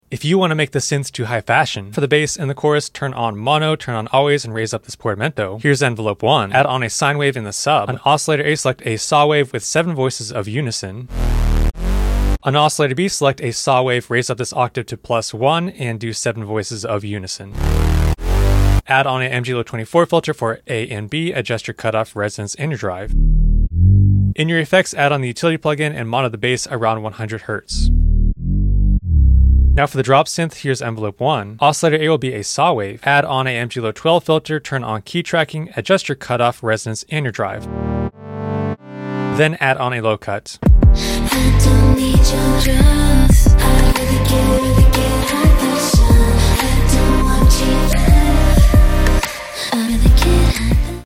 Bass & Drop Synth in Serum 2
bass, serum 2 tutorial